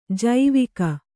♪ jaivika